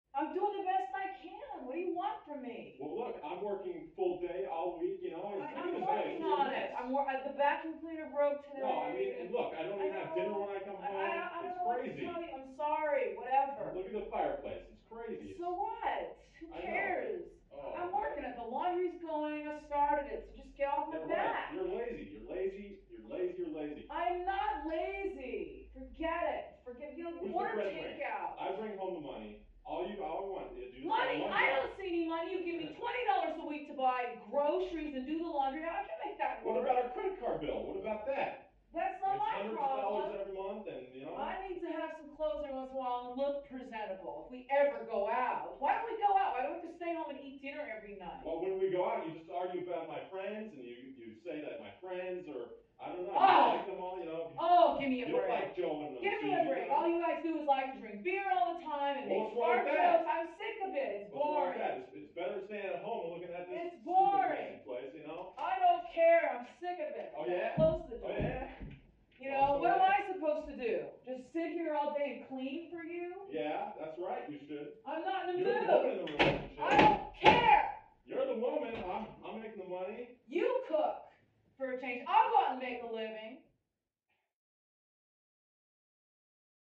Argue; Couple Yelling At Each Other, From Next Door, Some Door Slams And Pounding.